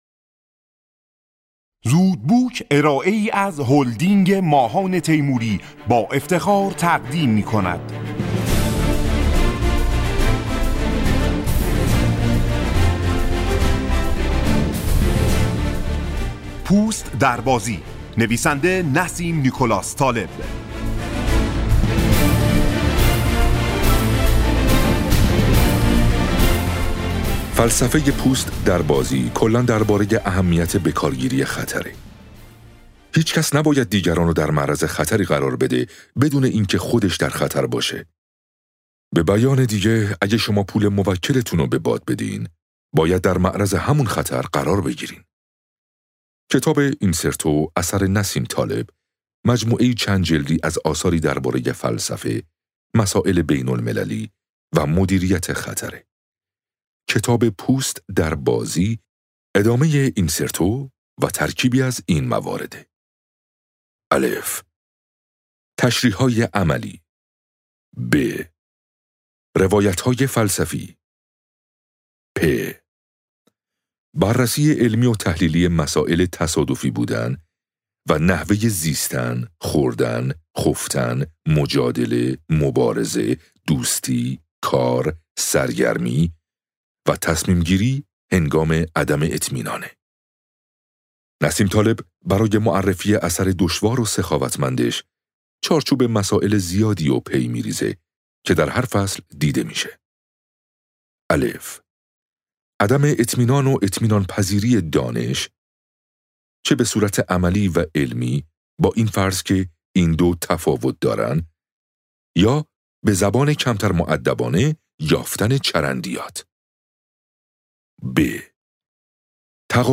خلاصه کتاب صوتی پوست در بازی